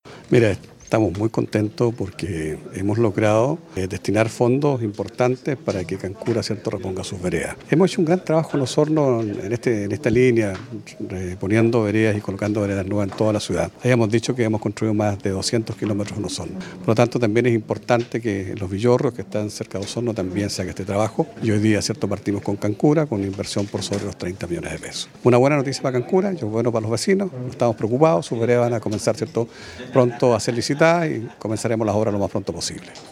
En este sentido el jefe comunal de Osorno, Jaime Bertin, explicó qu “se trata de una obra que viene a mejorar las vías de desplazamiento peatonal.